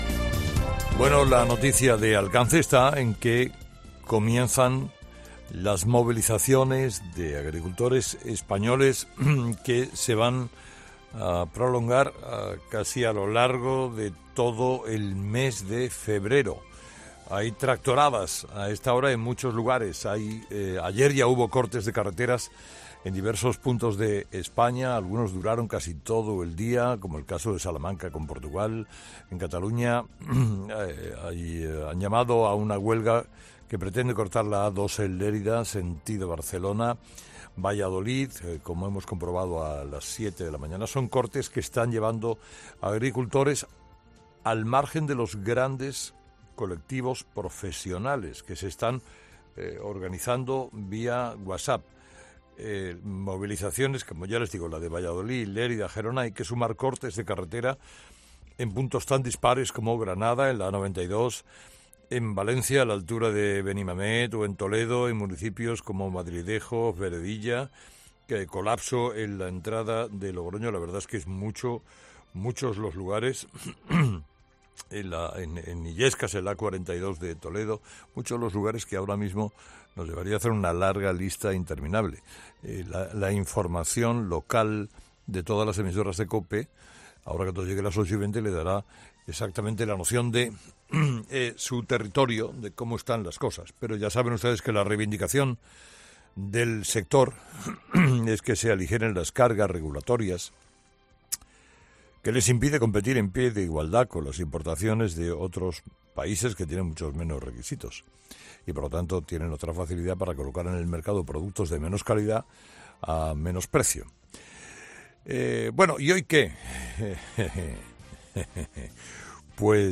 Escucha el monólogo de las 8 de Carlos Herrera del 6 de febrero de 2024